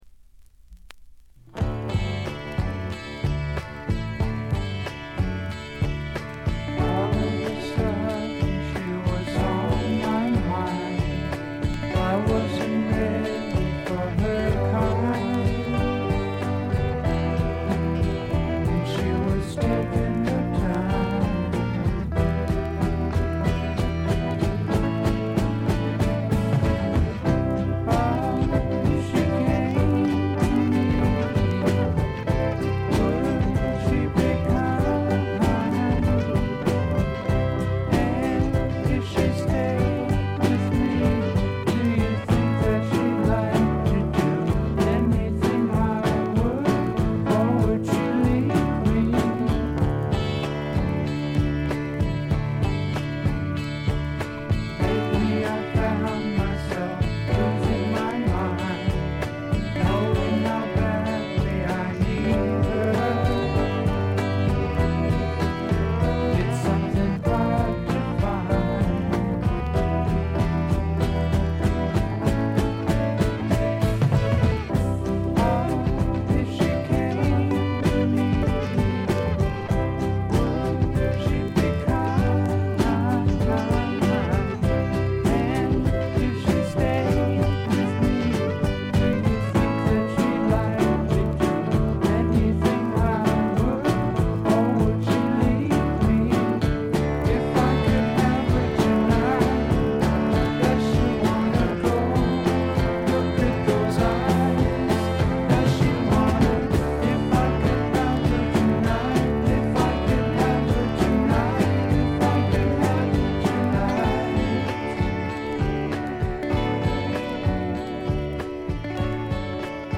静音部ところどころでチリプチ。散発的なプツ音少々。
試聴曲は現品からの取り込み音源です。